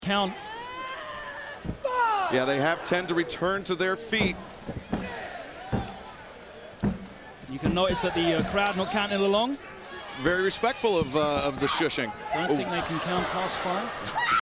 And when the fans didn’t count along with the referee during a key spot in the match, the announcers claimed the silence was given
out of respect.
respectful-crowd.mp3